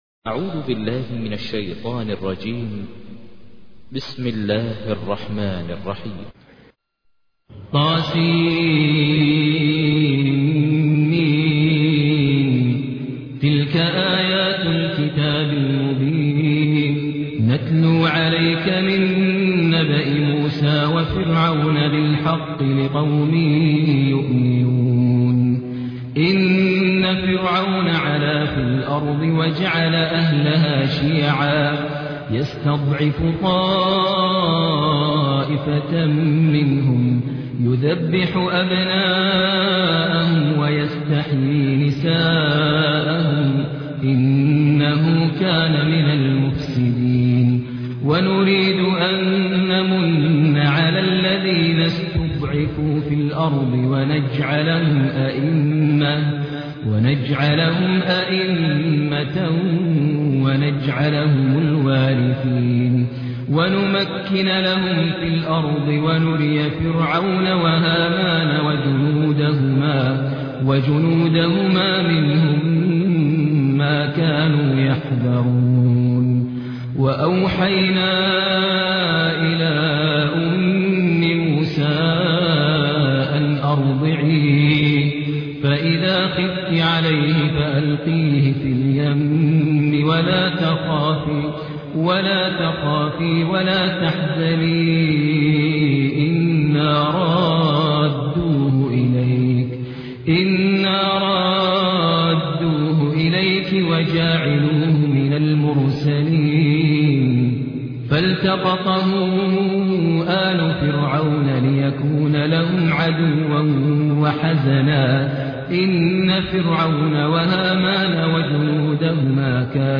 تحميل : 28. سورة القصص / القارئ ماهر المعيقلي / القرآن الكريم / موقع يا حسين